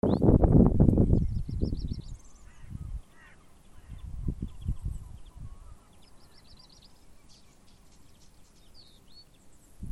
Birds -> Thrushes ->
Black Redstart, Phoenicurus ochruros
StatusVoice, calls heard